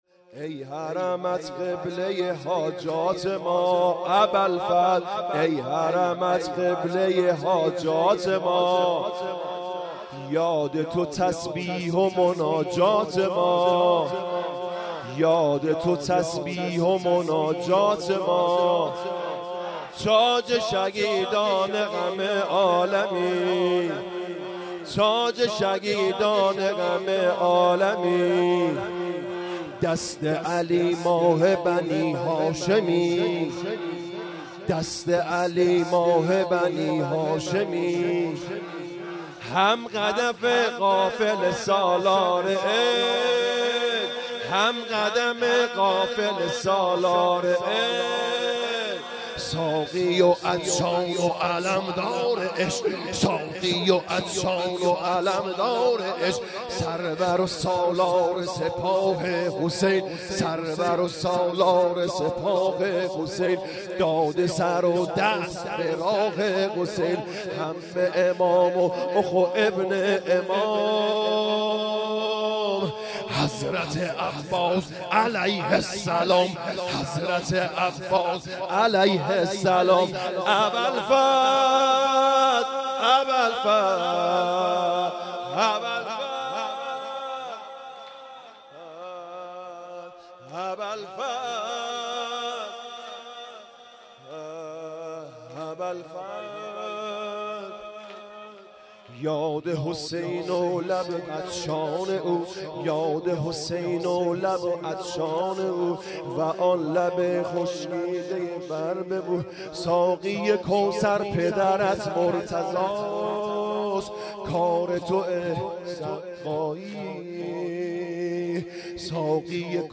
حسینیه کربلا